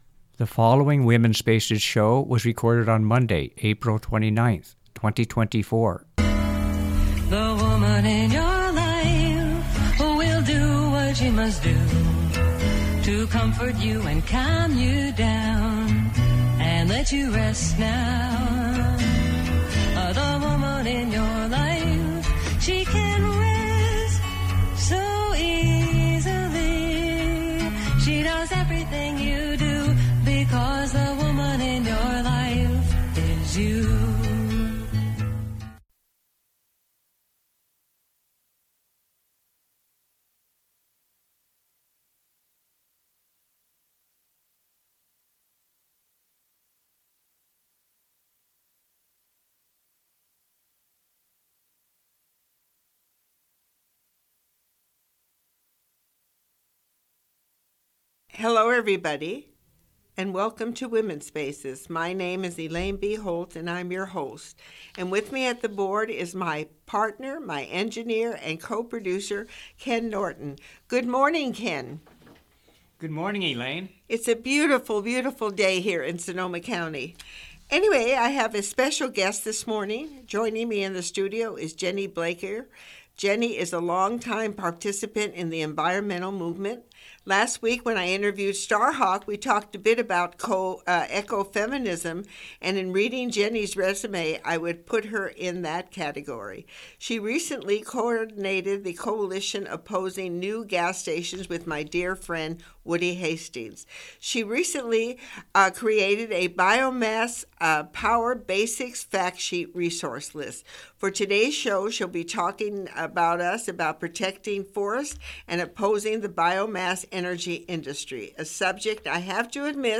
Commentary